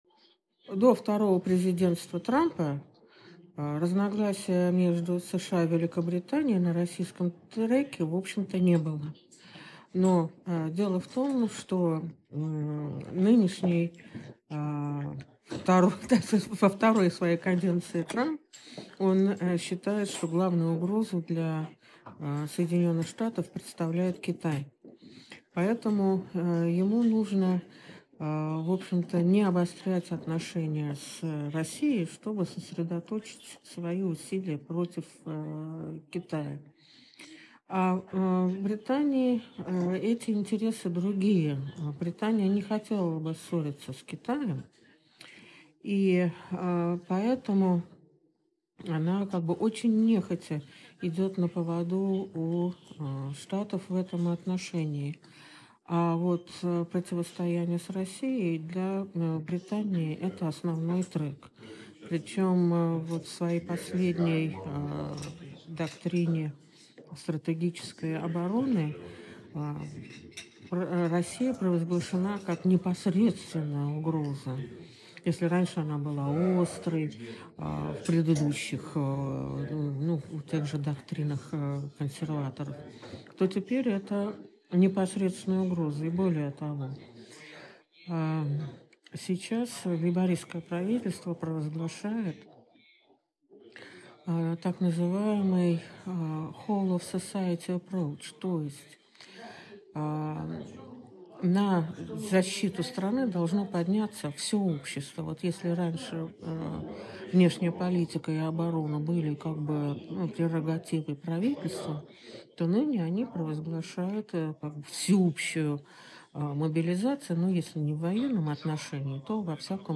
Эксперт участвовала в пятом международном форуме «СМИ и цифровые технологии перед вызовом информационного и исторического фальсификата», который прошел в Москве и был организован журналом «Международная жизнь» при поддержке Минцифры России.